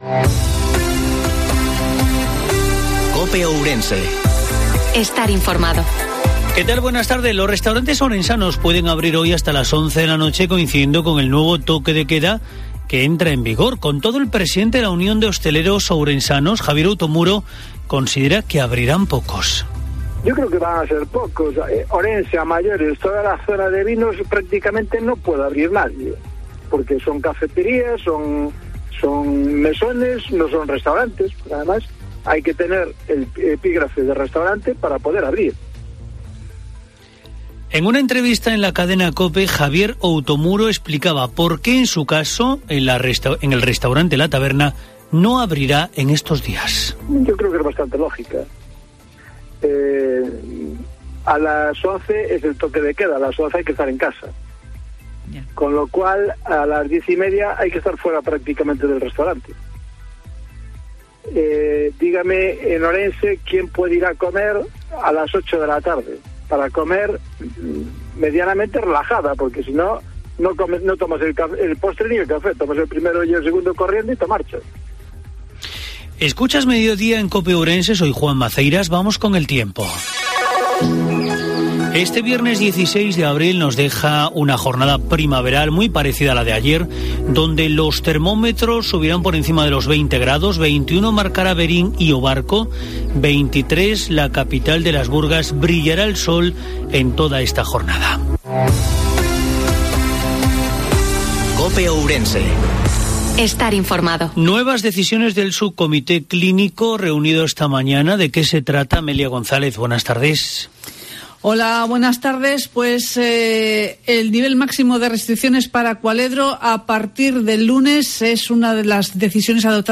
INFORMATIVO MEDIODIA COPE OURESE